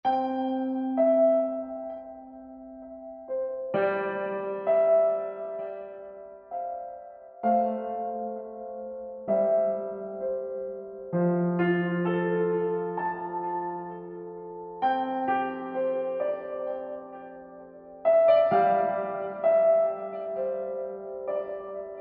Мелодичное звучание рояля